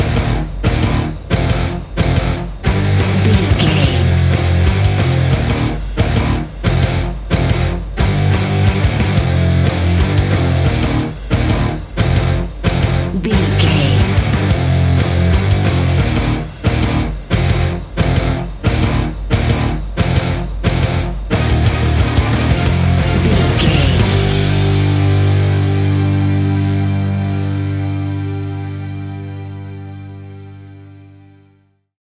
Epic / Action
Fast paced
Aeolian/Minor
hard rock
heavy metal
instrumentals
Heavy Metal Guitars
Metal Drums
Heavy Bass Guitars